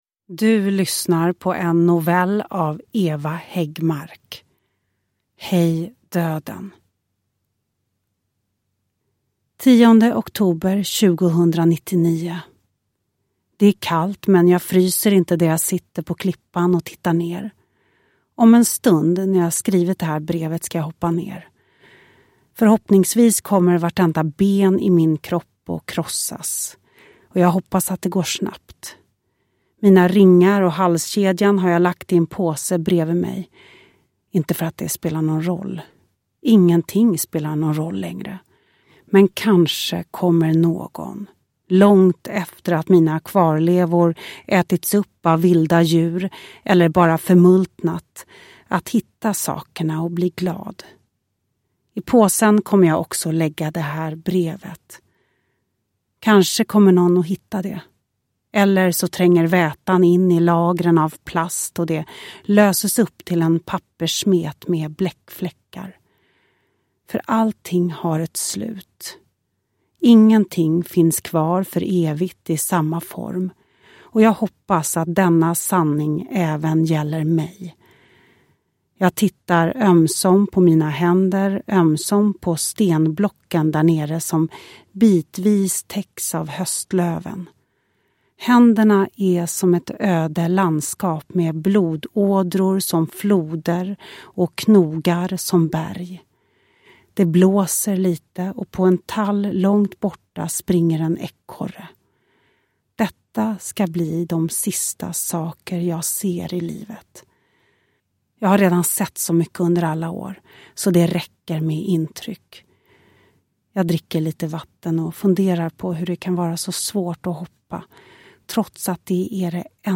Hej döden! : Mörkret utanför del 2 – Ljudbok – Laddas ner
Uppläsare: Lo Kauppi